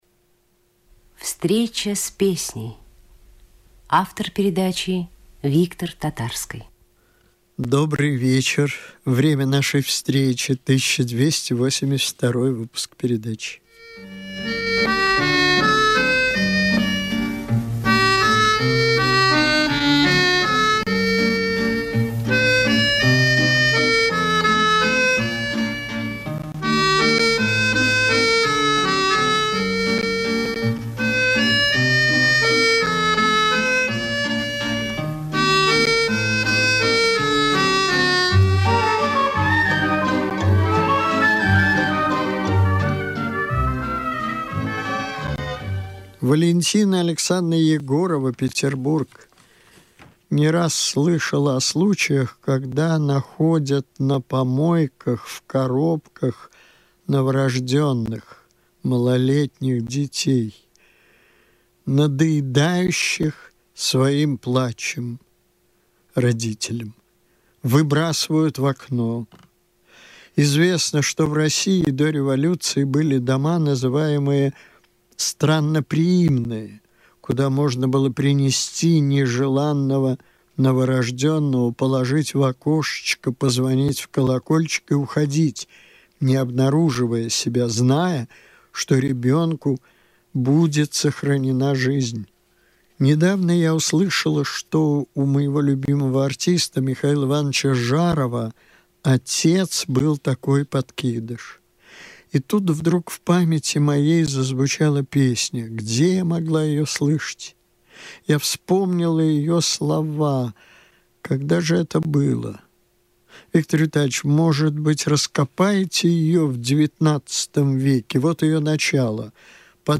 Ваш собеседник - Виктор Татарский